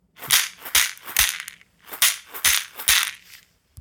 ひょうたん底 バスケットマラカス　アフリカ 民族楽器 （p070-23）
ブルキナファソで作られたバスケット素材の素朴なマラカスです。
水草とひょうたんと木の実でできています。
やさしいナチュラルな乾いた音を出します。
この楽器のサンプル音